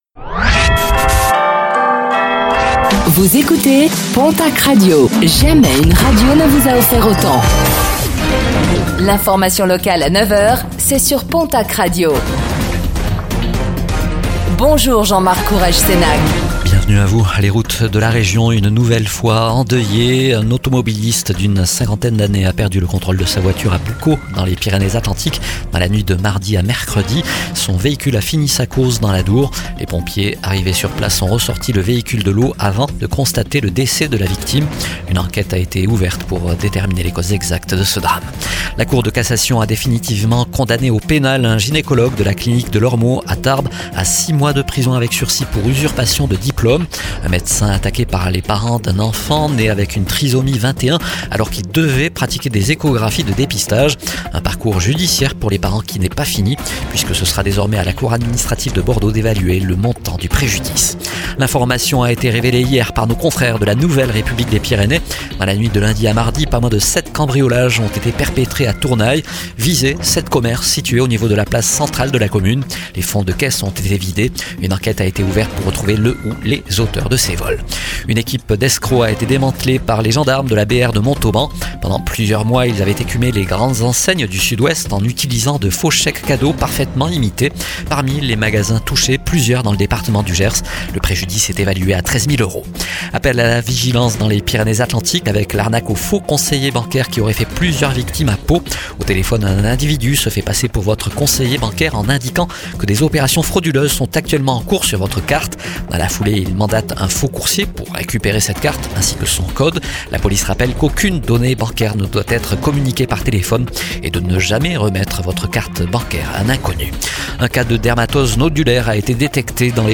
Infos | Jeudi 11 décembre 2025